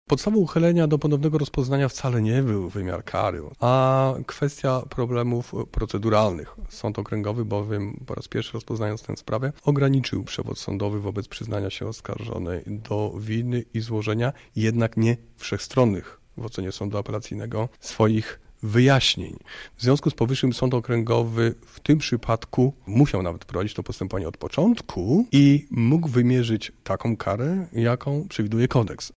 Rozpatrujący tę sprawę ponownie Sąd Okręgowy w Lublinie uchylił poprzednie orzeczenie i wymierzył dzisiaj oskarżonej karę 14 lat więzienia – mówi rzecznik prasowy sędzia Dariusz Abramowicz.